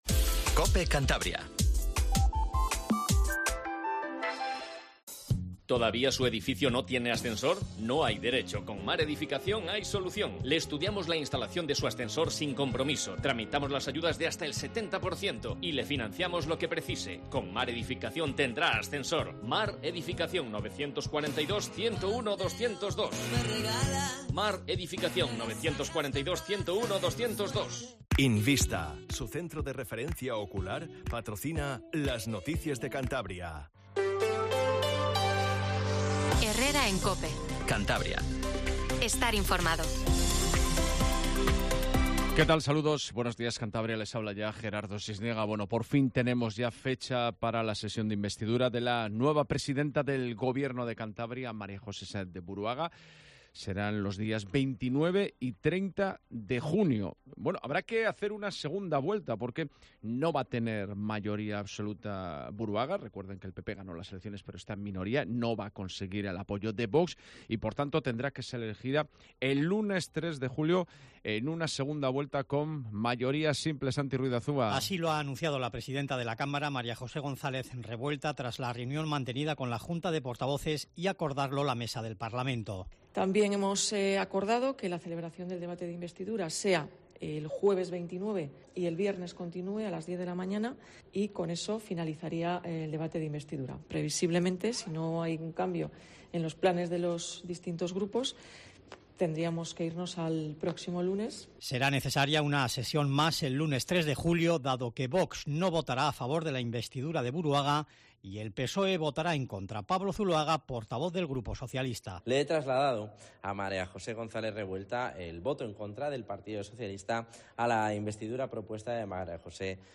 Informativo Matinal Cope 07:50